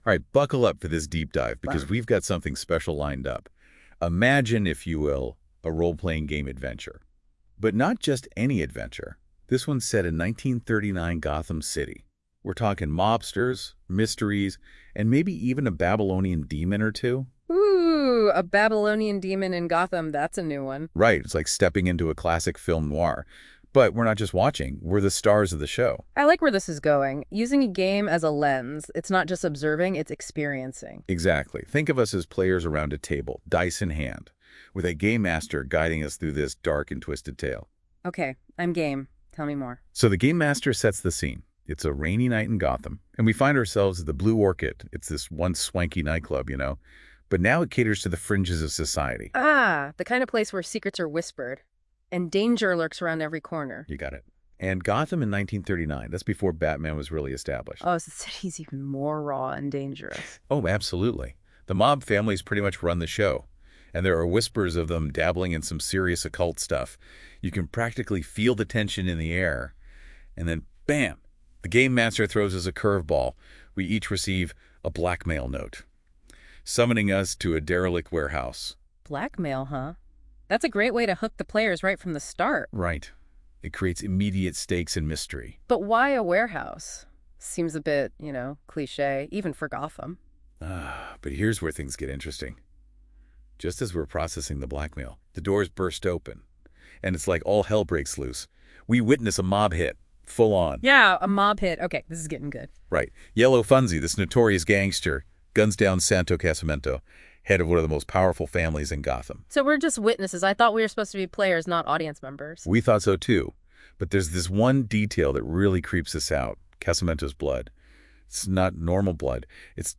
And I had a blast listening to its podcast output, which let me find even more errors, when the “hosts” talked about a character motivation that was simply not true. If you want, you can listen to it here , which gives a nice overview of the adventure in about 15 minutes.